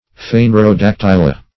Search Result for " phanerodactyla" : The Collaborative International Dictionary of English v.0.48: Phanerodactyla \Phan`er*o*dac"ty*la\, n. pl.
phanerodactyla.mp3